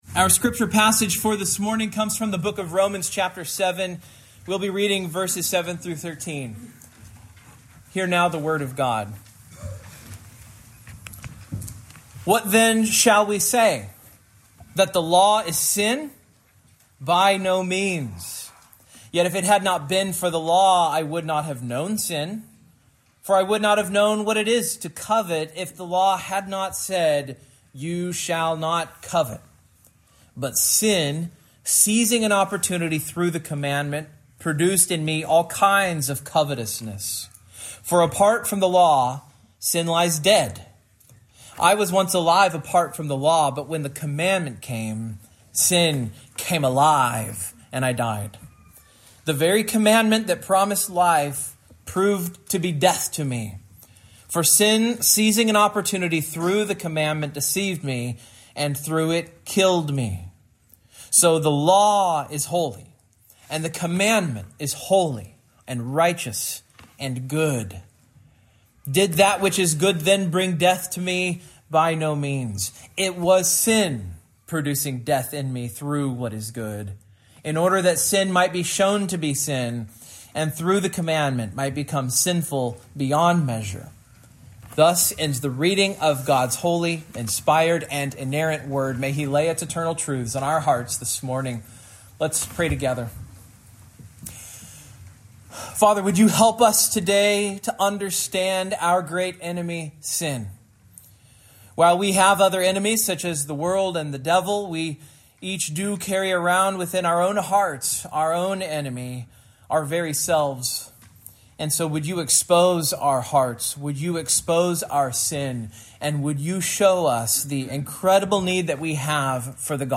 Romans 7:7-13 Service Type: Morning Main Point